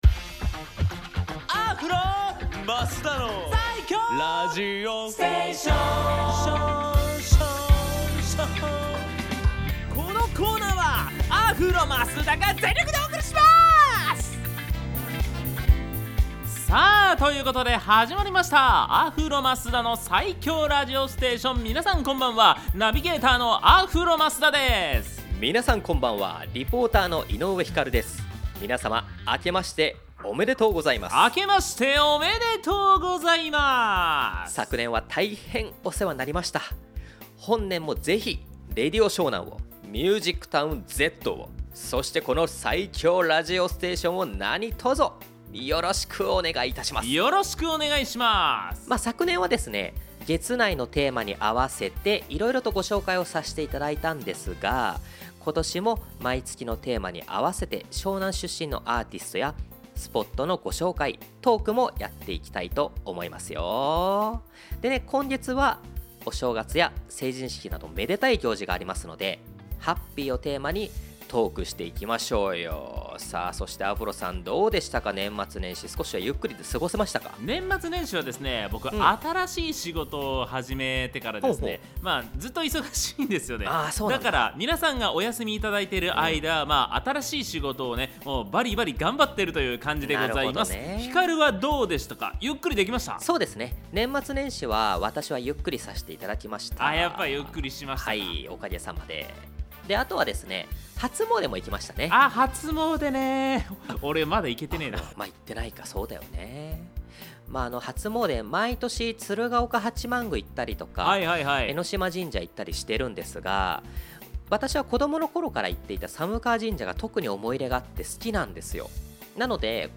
放送音源はこちら